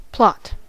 plot: Wikimedia Commons US English Pronunciations
En-us-plot.WAV